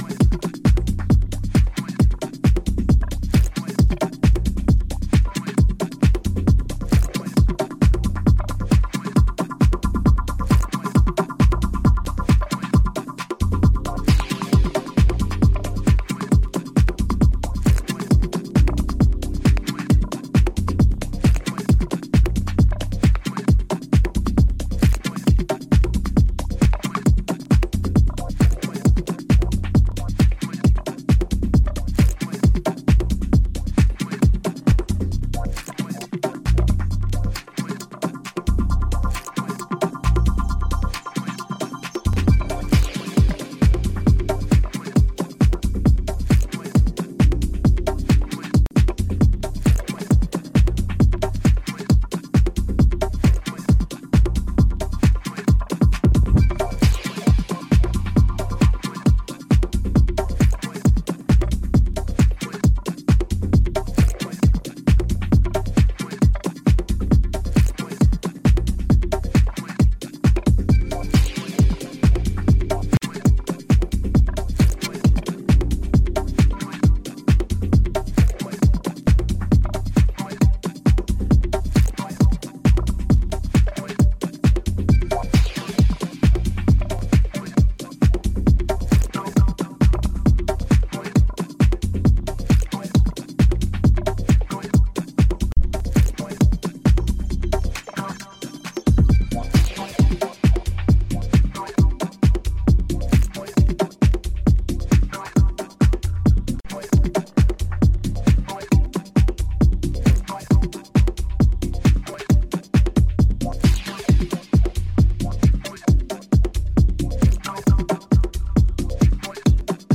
軽快なアップテンポのハウス
やはり今回も90’s IDMの精神性を感じさせる美的センスが横溢。